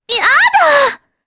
Epis-Vox_Damage_jp_03.wav